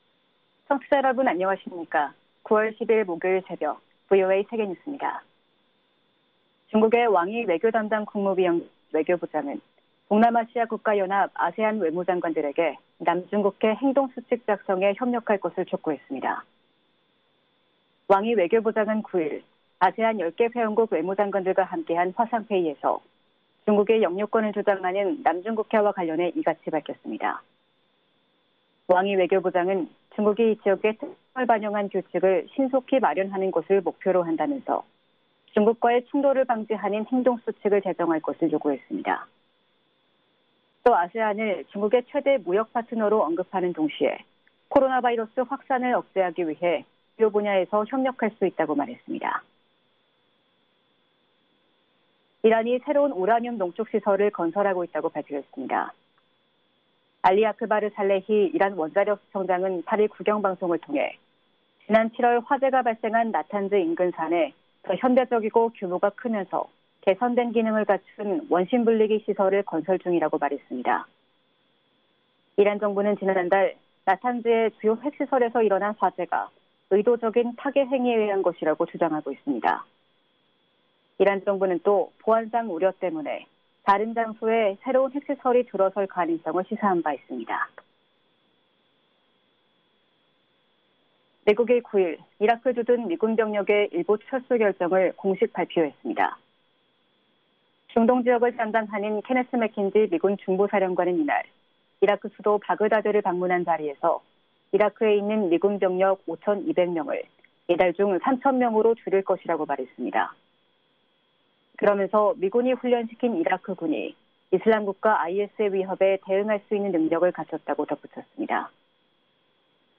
VOA 한국어 '출발 뉴스 쇼', 2020년 9월 10일 방송입니다. 미 육군 참모총장이 신종 코로나바이러스 영향으로 당장 한반도에서 대규모 연합훈련을 재개하기는 어려운 실정이라고 밝혔습니다. 미국과 한국의 안보 보좌관이 어제 전화 통화를 갖고 한반도 평화프로세스 진전과 신종 코로나바이러스 감염증 협력 대응 등에 대해 논의했습니다. 지난 2018년 싱가포르 미-북 정상회담 때 일화들이 백악관 전 대변인의 회고록을 통해 공개됐습니다.